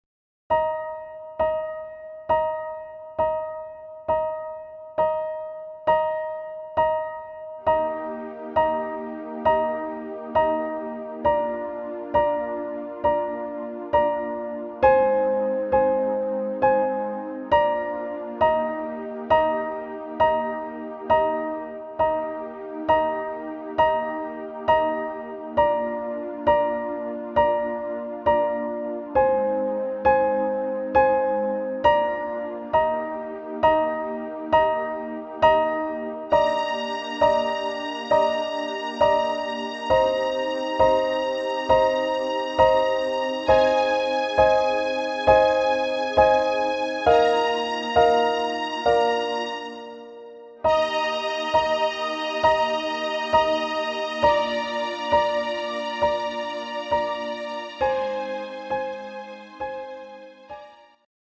Piano-Hochzeitsversion